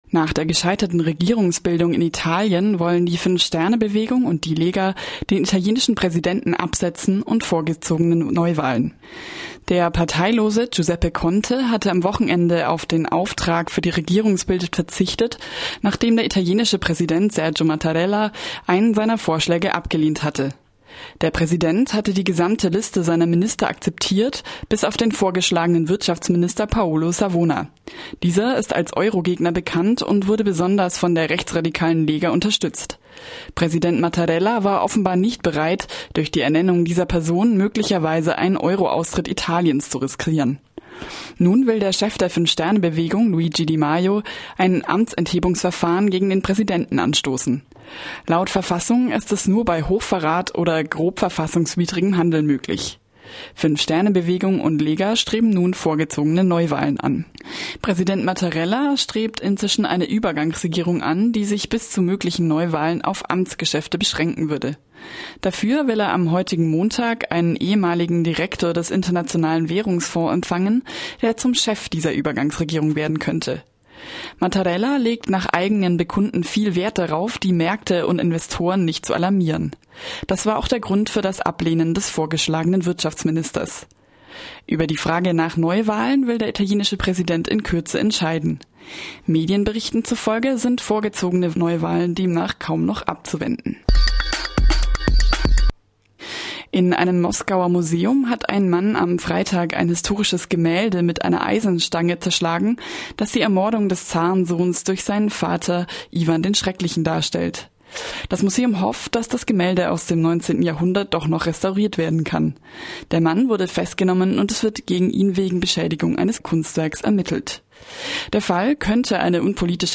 Focus Europa Nachrichten am Montag, 28. Mai 2018